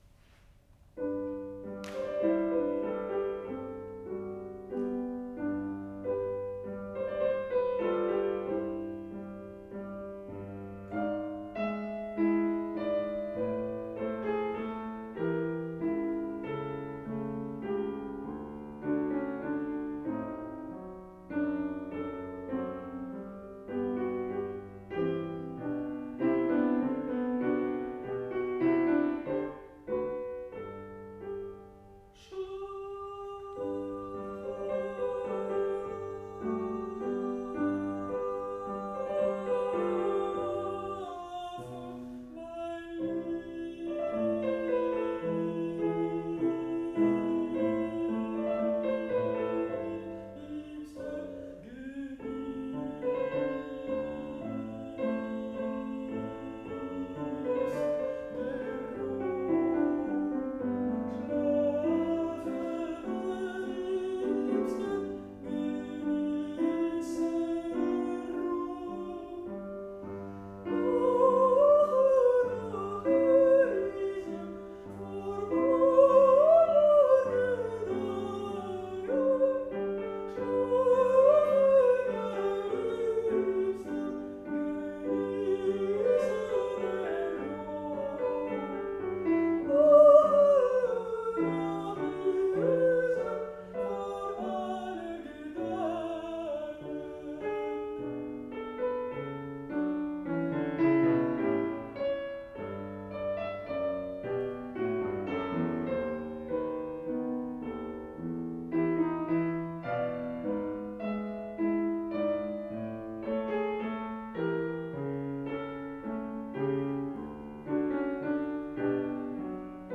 International Summer Academy of Music, Nice, France